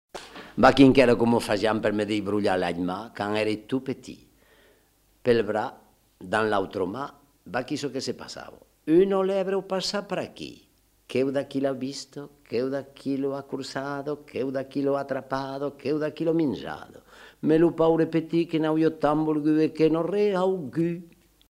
Formulette sur les doigts
Lieu : Lauzun
Genre : forme brève
Type de voix : voix d'homme
Production du son : récité
Classification : formulette enfantine